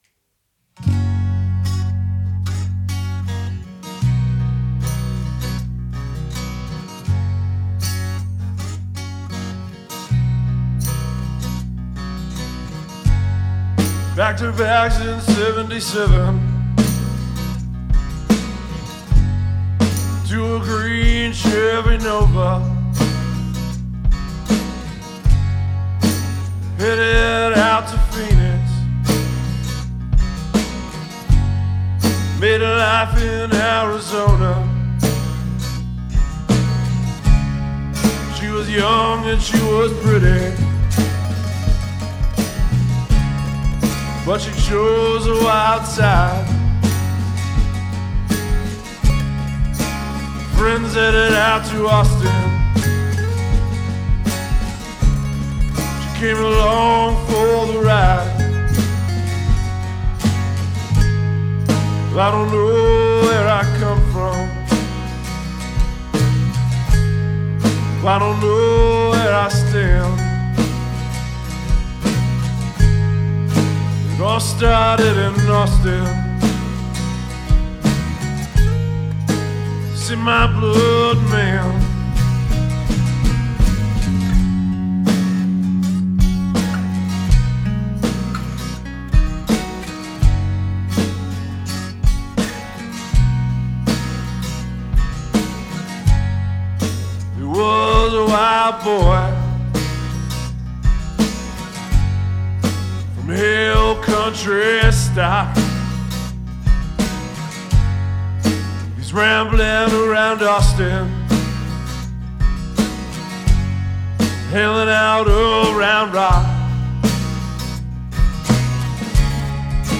So, anyway, I started on a new project recently and I recorded a scratch track (its a band, but I play everything, so I had to recording each track separately) without a click track, the idea being that I didnt want it to sound so stiff.
I added more guitars, I got great sounding vocals, bass, a drum part, etc etc., but I'm really listening to this, and I feel like there are just parts that are really off time. It's a complex guitar part, and I just dont seem to be playing it exactly the same each time around.
Song (which isnt done mixing) is attached.